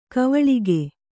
♪ kavaḷige